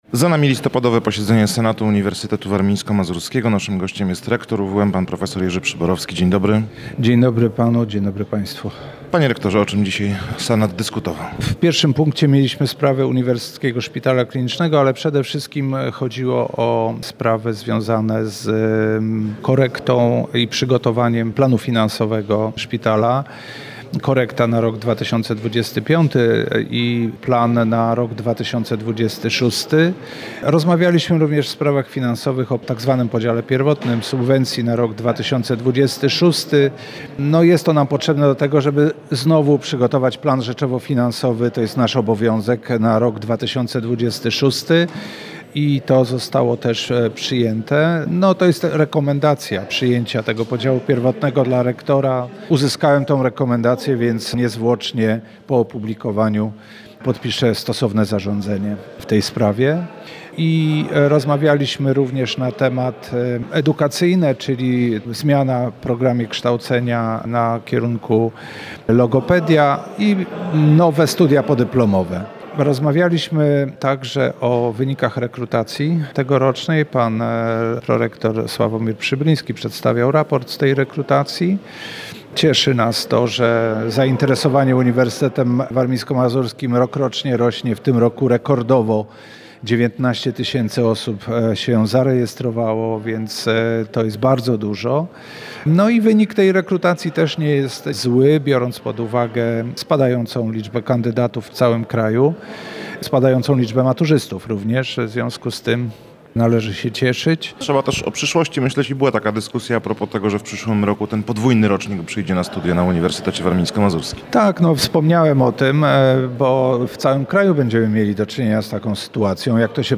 O to zapytaliśmy rektora UWM prof. Jerzego Przyborowskiego.
2811-MA-Jerzy-Przyborowski.mp3